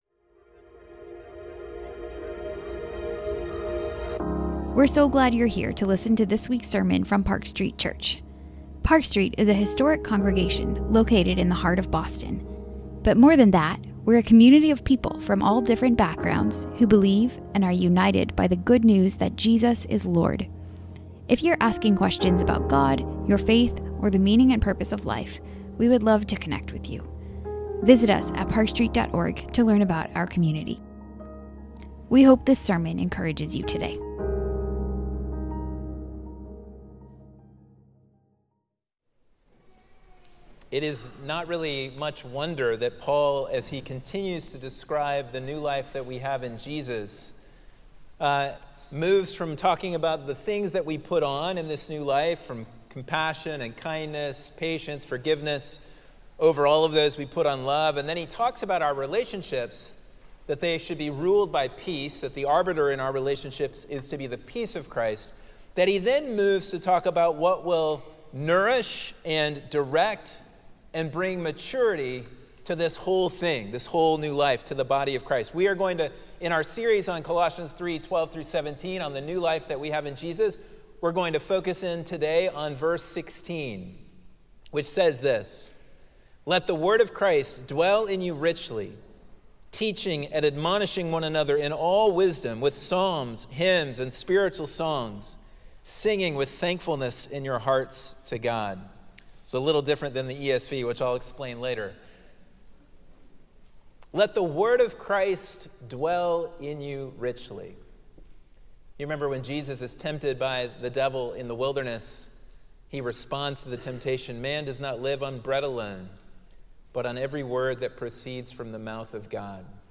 In contrast to a world filled with conflict and division, the new life to which we are called in Christ is to be ruled by the peace of Christ. This sermon explores the nature of this peace, what it means for this peace to rule over us, and why this is fitting given that we are one body.